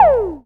SYN TOM.wav